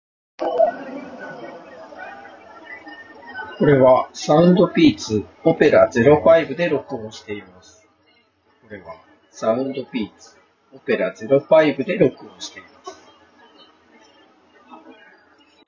Opera 05にはノイキャン（ANC）に加えて通話時のノイズキャンセル機能もあるので、効果を確かめるために実際にマイクで録音してみました。
スピーカーから雑踏音をそこそこ大きなボリュームで流しながら収録しています。
音声はAirPods Pro（第2世代）の方がクリアですが、周囲の雑音はOpera 05の方がよく消えてるように感じます。